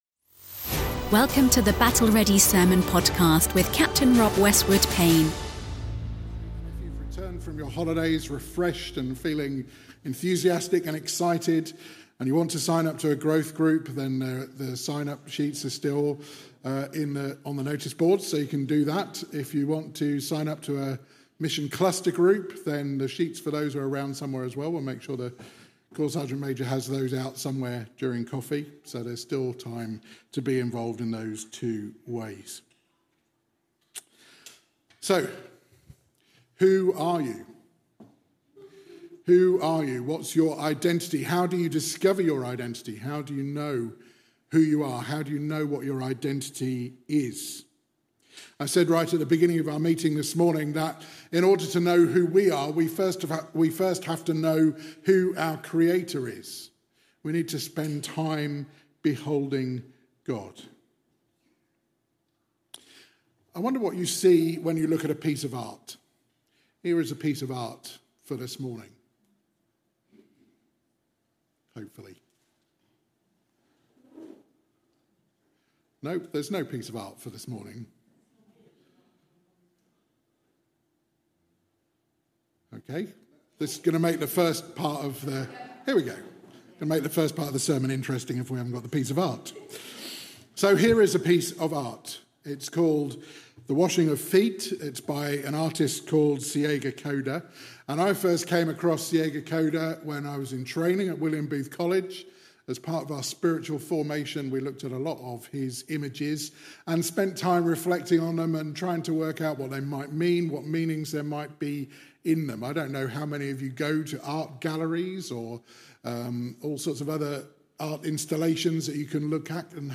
Battle Ready Sermons